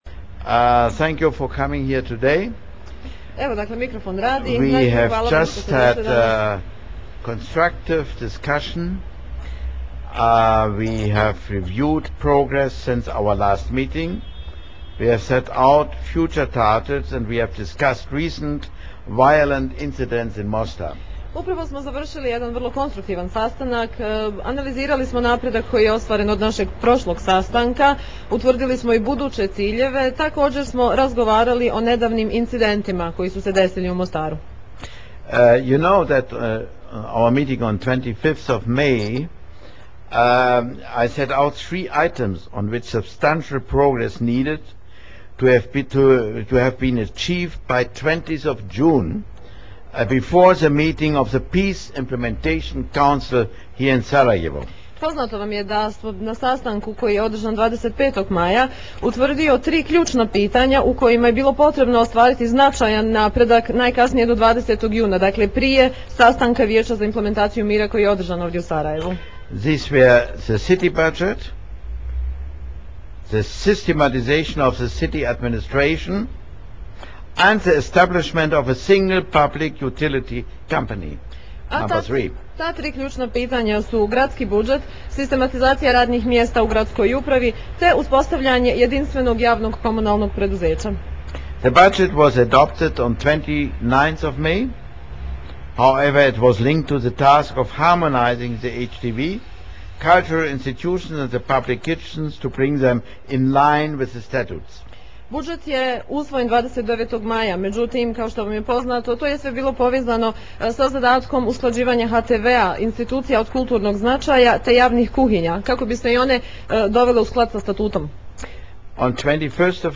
Transcript of the High Representative’s Press Conference following Meeting with Mostar Officials